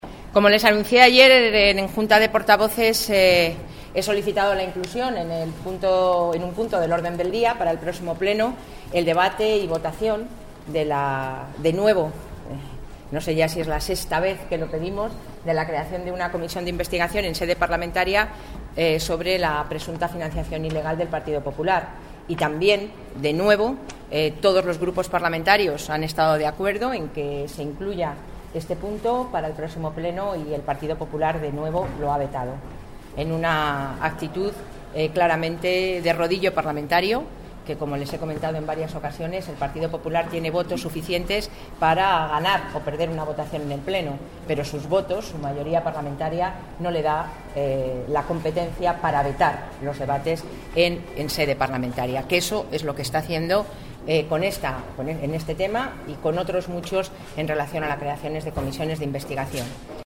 Declaraciones de Soraya Rodríguez el 18/06/14. El PP vuelve a negarse a que se debata en el Congreso la creación de una comisión parlamentaria para investigar su presunta financiación ilegal